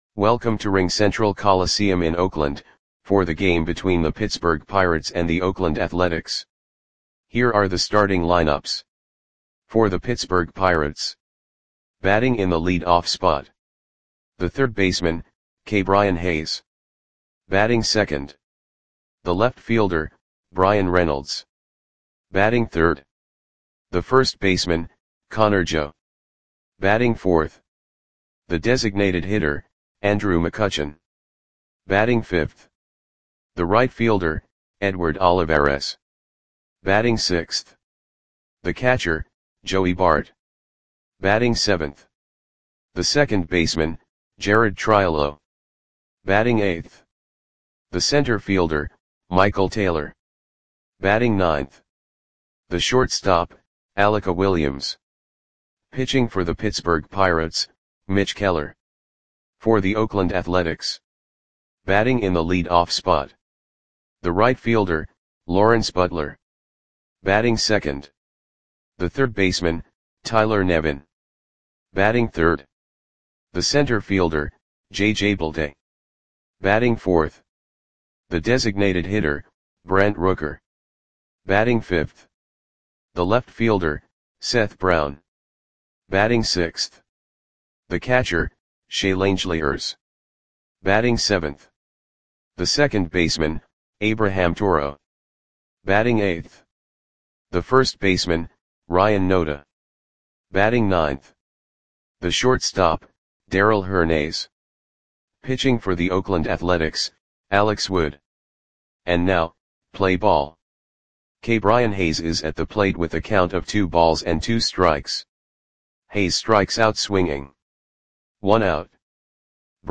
Audio Play-by-Play for Oakland Athletics on April 30, 2024